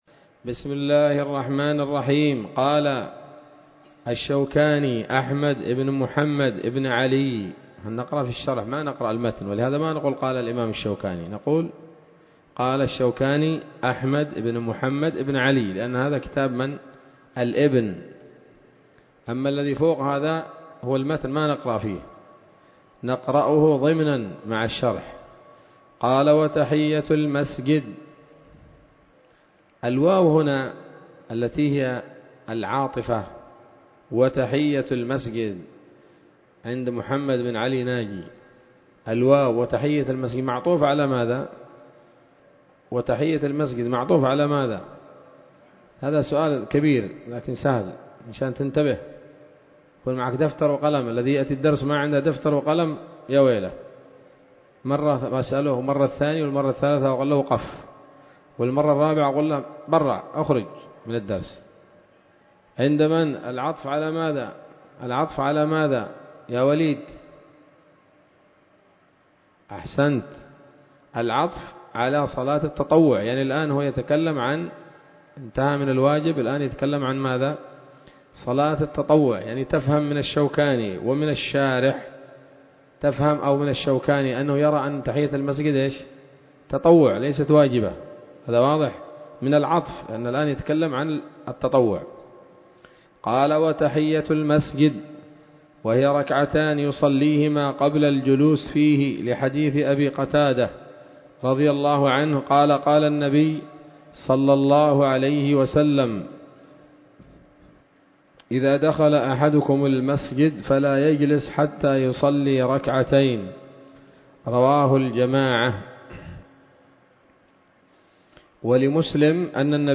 الدرس الثالث والعشرون من كتاب الصلاة من السموط الذهبية الحاوية للدرر البهية